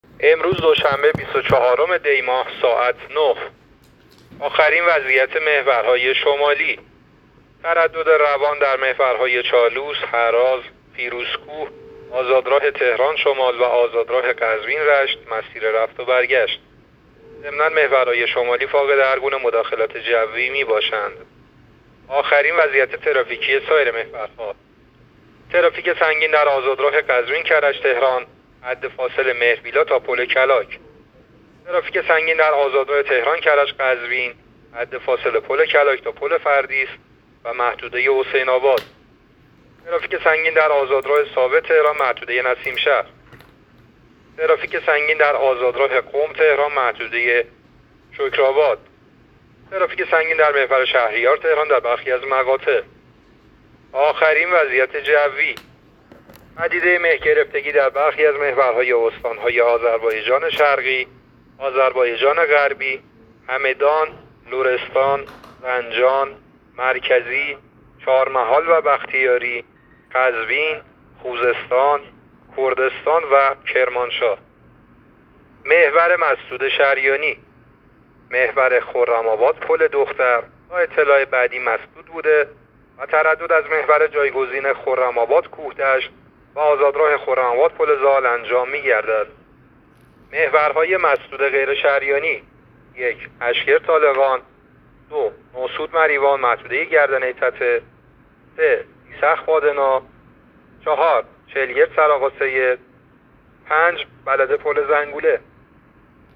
گزارش رادیو اینترنتی از آخرین وضعیت ترافیکی جاده‌ها تا ساعت ۹ بیست‌وچهارم دی؛